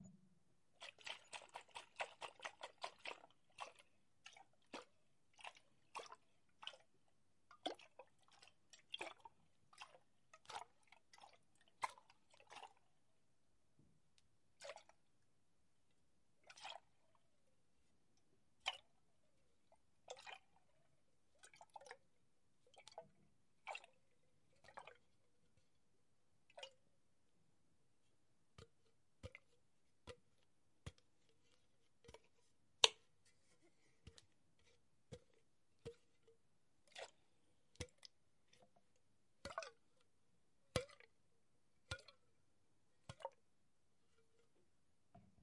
瓶摇动
描述：摇动铁瓶
Tag: 晃动 霍霍 摇匀